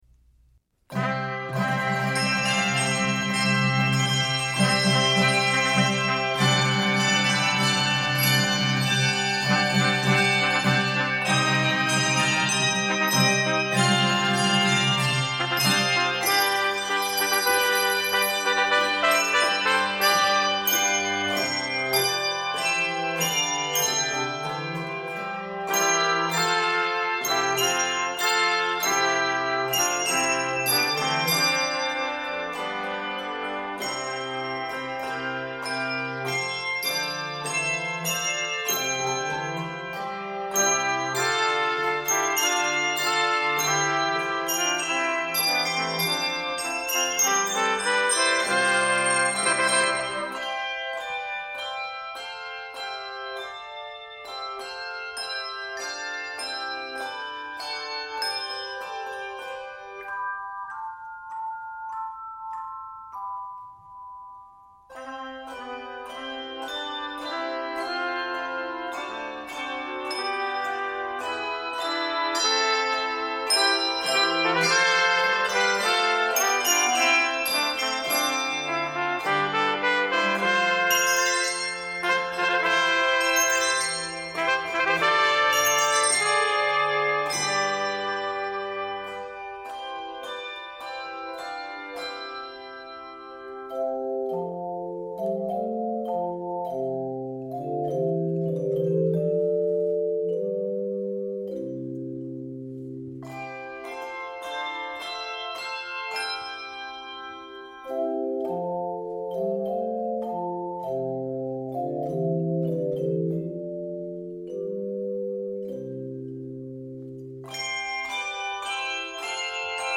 Keys of C Major, Bb minor, and, Eb Major.
Octaves: 3-7